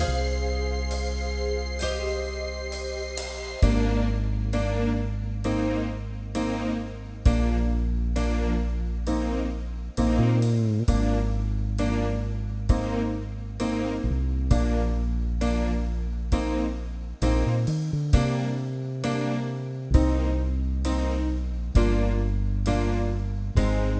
No Main Guitar Rock 6:22 Buy £1.50